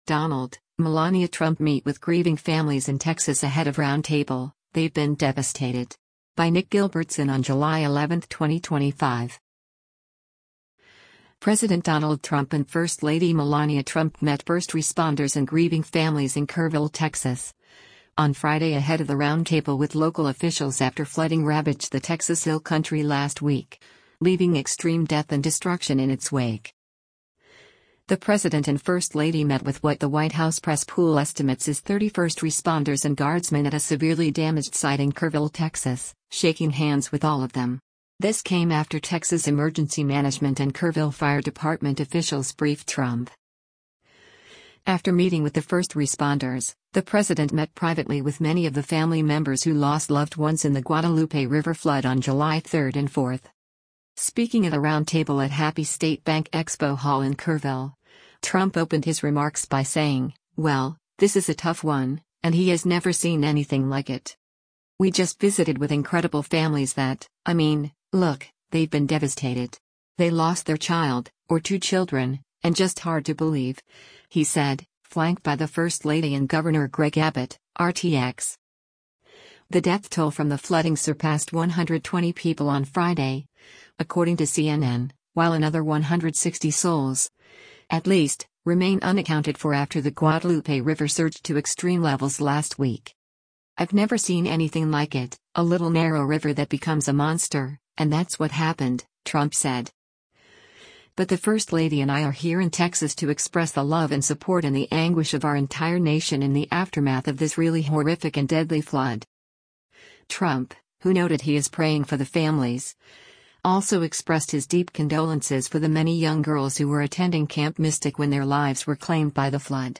Speaking at a roundtable at Happy State Bank Expo Hall in Kerrville, Trump opened his remarks by saying, “Well, this is a tough one,” and he has “never seen anything like it.”